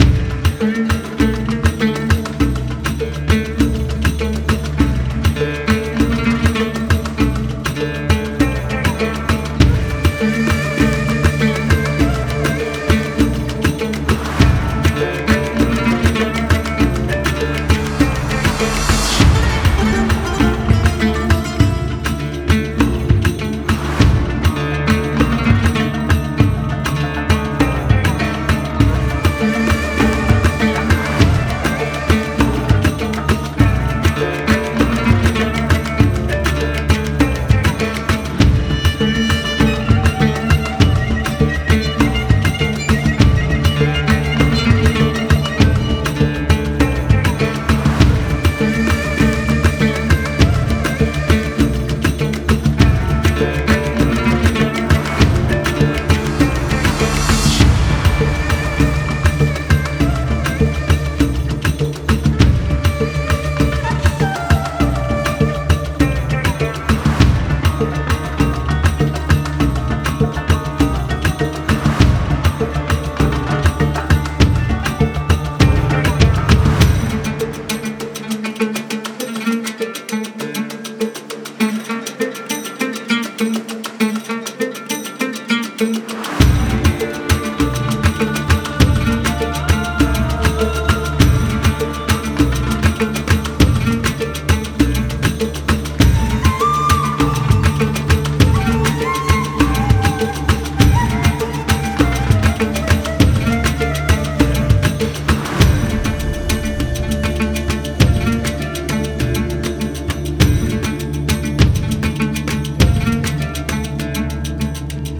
Fantasy Music - The Eternal Sands
Hello,Something that could be used as a battle theme or a Desert ruin or town. Here is the .wav and .ogg and it is loopable.